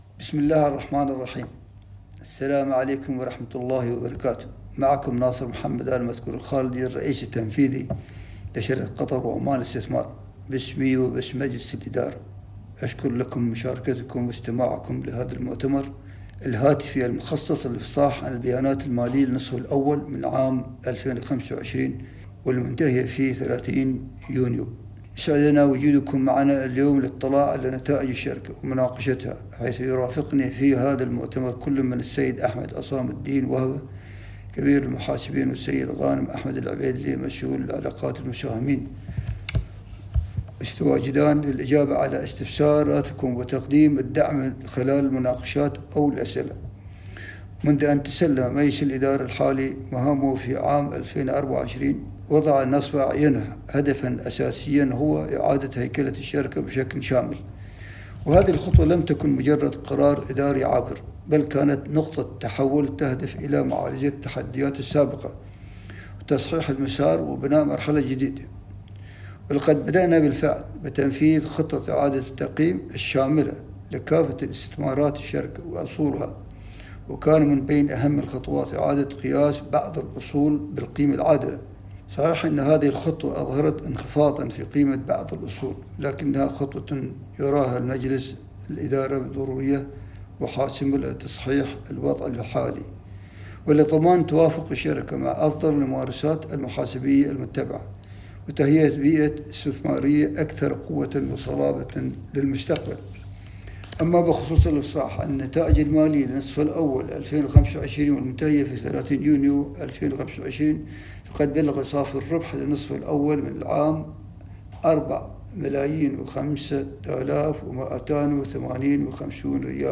المكالمة الجماعية